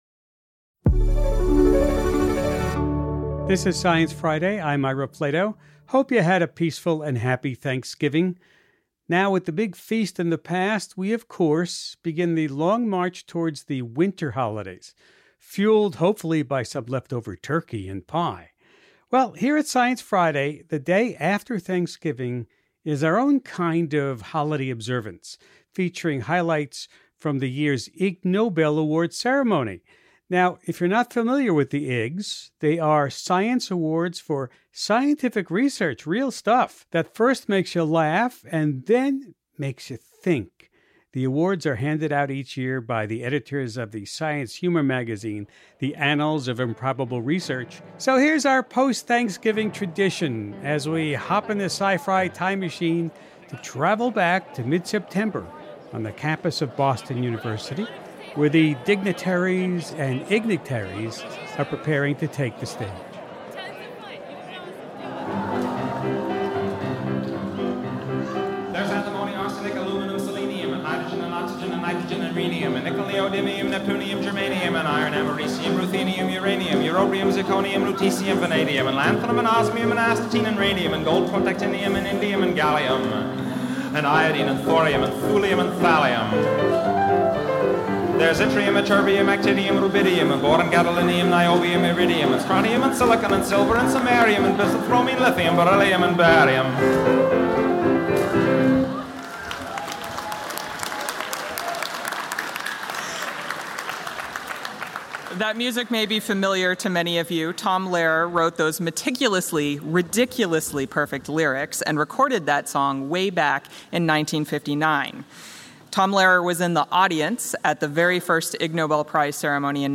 As is Thanksgiving tradition, we’re sharing highlights from this year’s Ig Nobels on Science Friday.